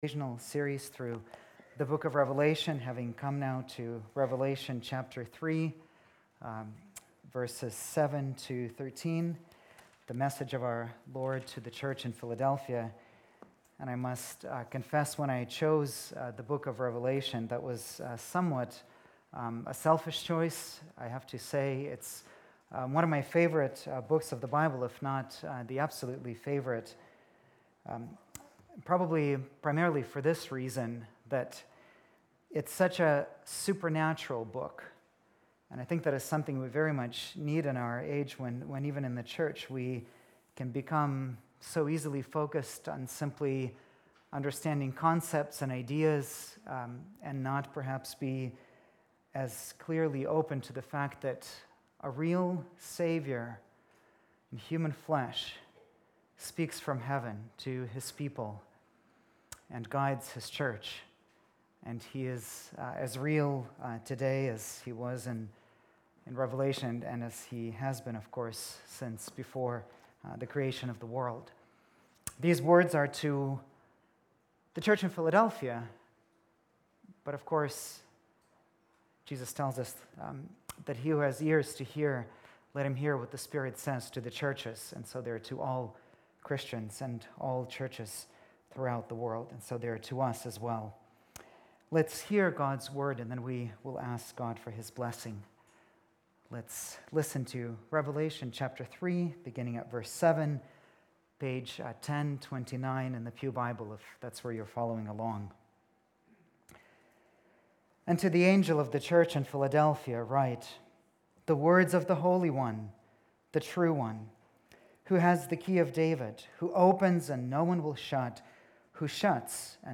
October 14, 2018 (Sunday Morning)